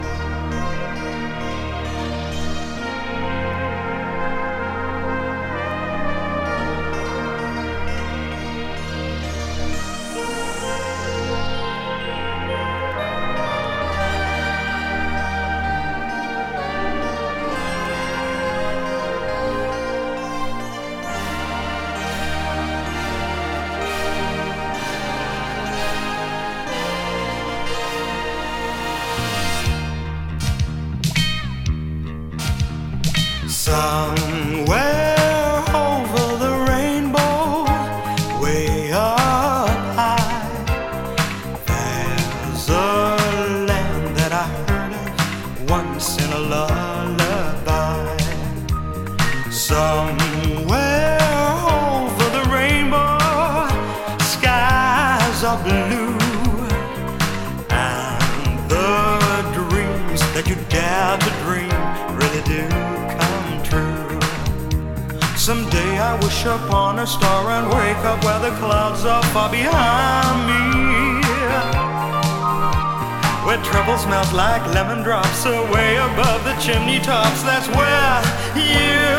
タンゴもファンクも、ギターサウンド、日本風のものまで。様々な要素がミックスされたエレポップ！